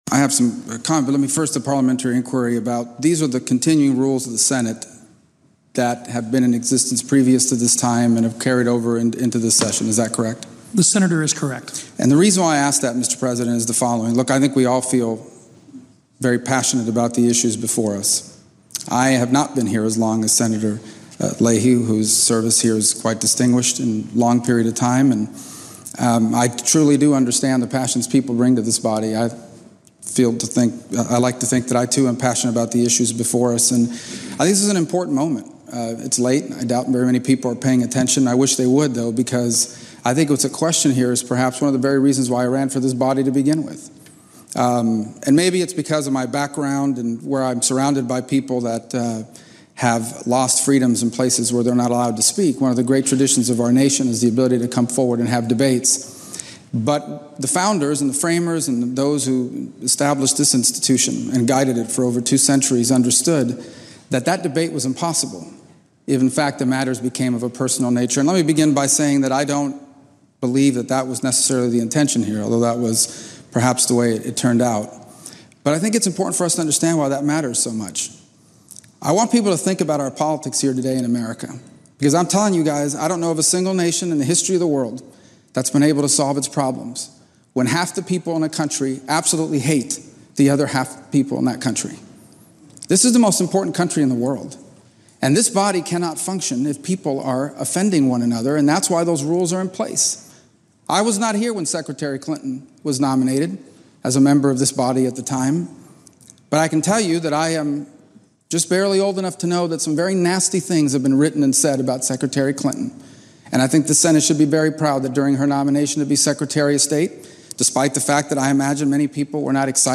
Senate Floor Speech on Civility in the Senate
delivered 7 February 2017, Washington, D.C.